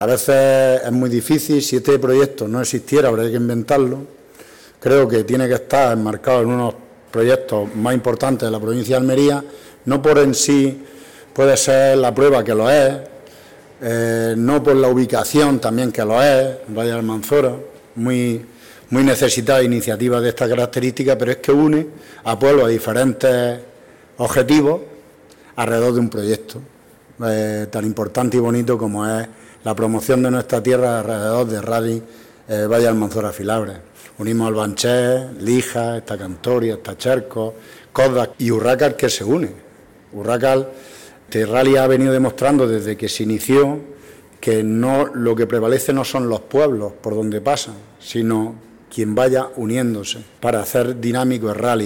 09-03_rallye_alcalde_olula.mp3.mp3